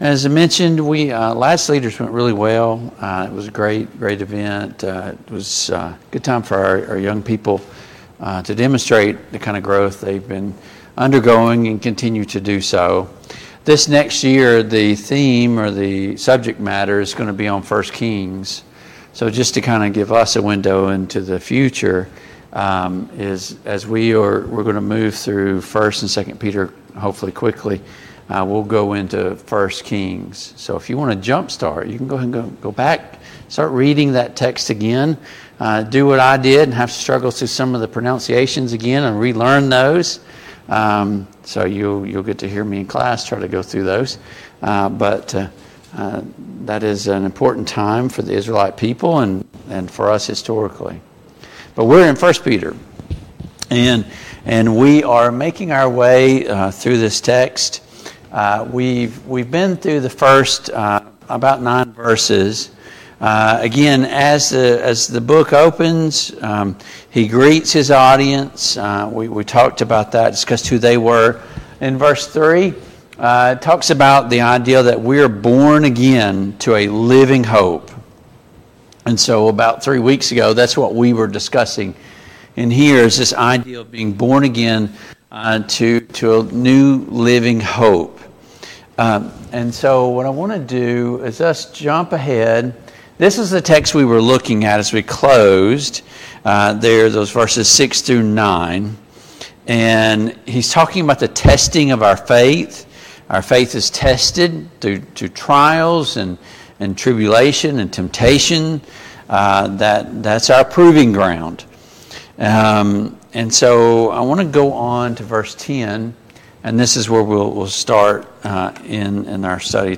Passage: 1 Peter 1:10-25 Service Type: Family Bible Hour